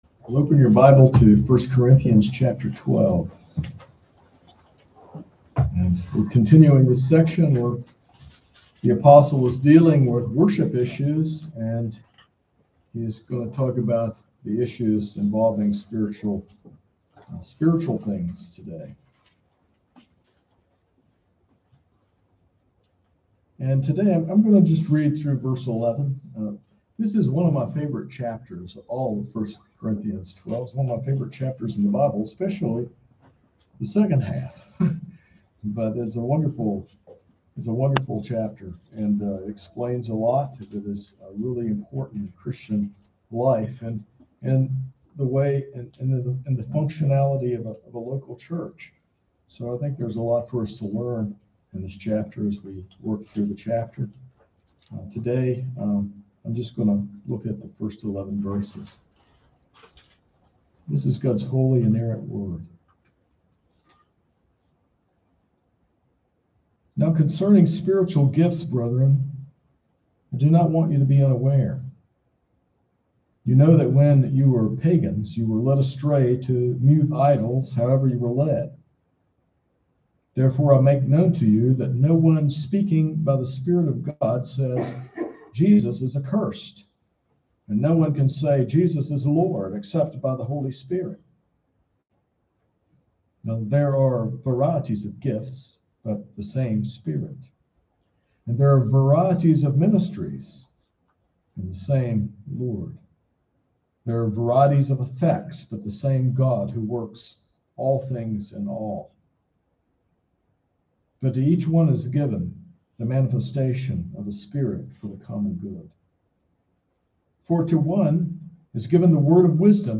This sermon explores 1 Corinthians 12:1-11, defining spiritual gifts as abilities from God for the common good of the church, emphasizing their purpose to glorify Christ and build up the body. It contrasts spiritual control with worldly frenzies and highlights God’s quiet, powerful work.